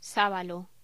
Locución: Sábalo
locución
Sonidos: Voz humana